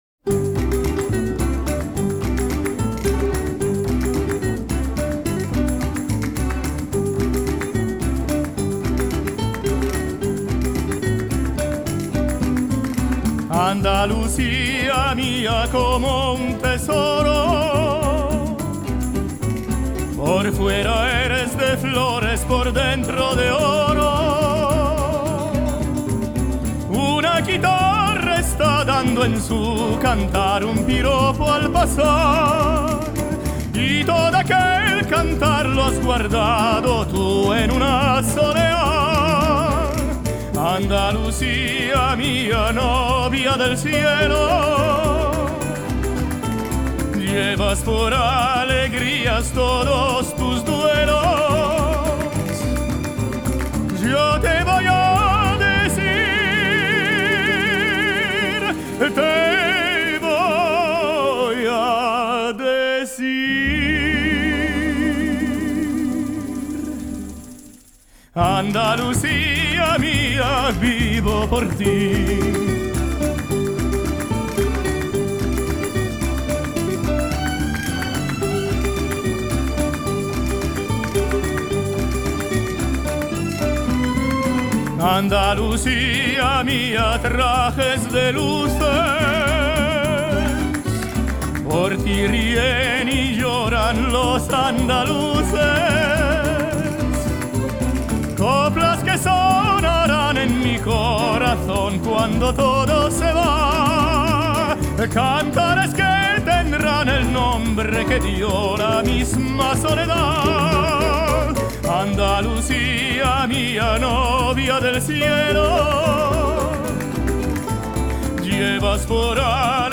Me traigo a un importantísimo tenor mundial